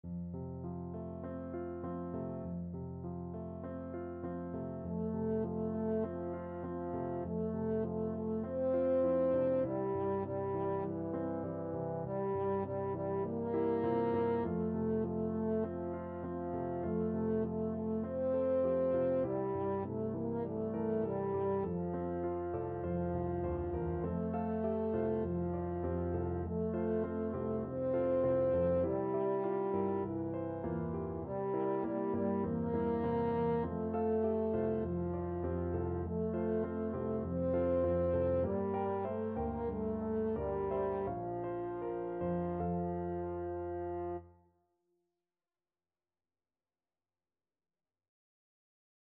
French Horn
F major (Sounding Pitch) C major (French Horn in F) (View more F major Music for French Horn )
Gently Flowing = c.100
4/4 (View more 4/4 Music)
Traditional (View more Traditional French Horn Music)